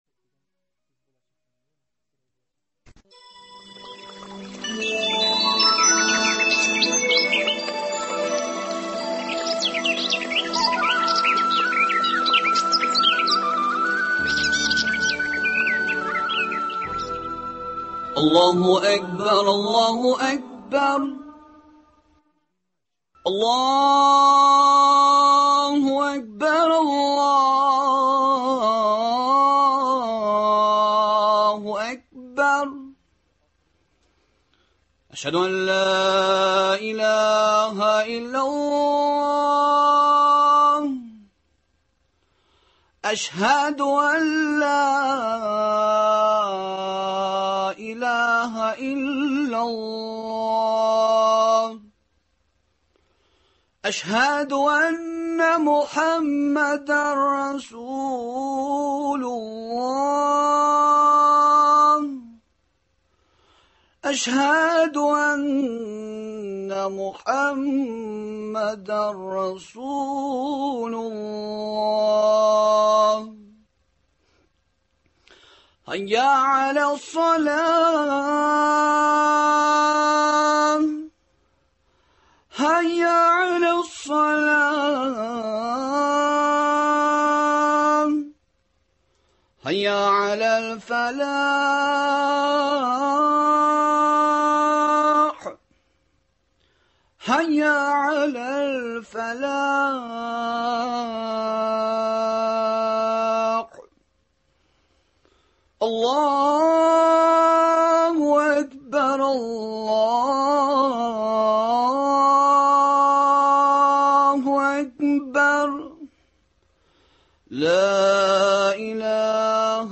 Студиябездә кунакта